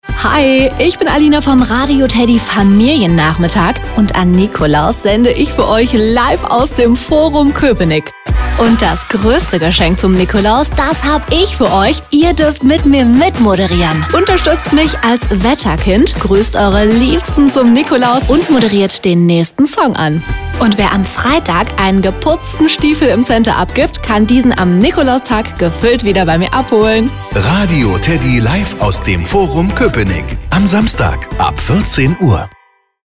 Nikolaus-im-Forum-Koepenick-Weihnachten-Weihnachtszauber-Radio-TEDDY-Livesendung-Promotion-Spot.mp3